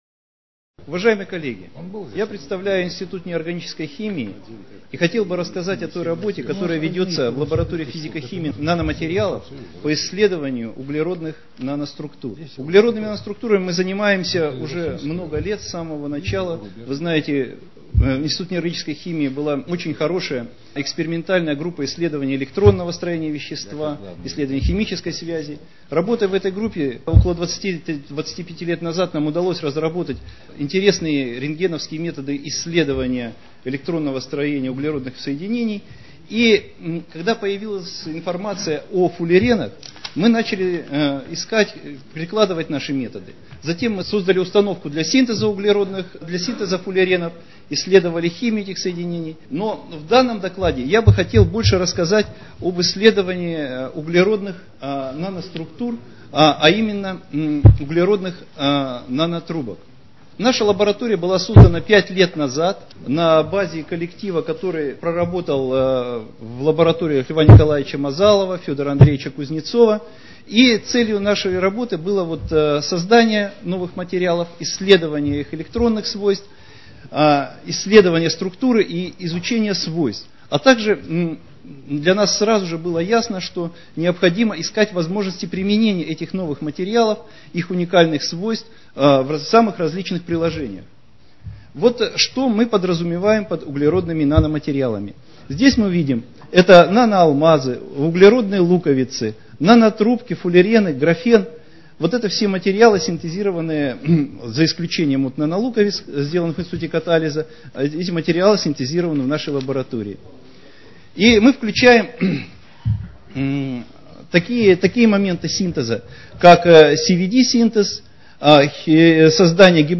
Аудиозапись доклада в формате mp3 – в прикрепленном файле.